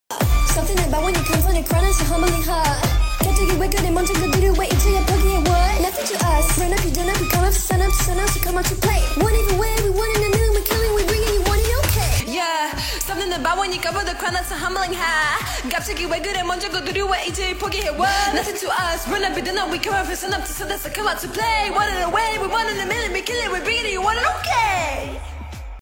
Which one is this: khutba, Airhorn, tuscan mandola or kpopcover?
kpopcover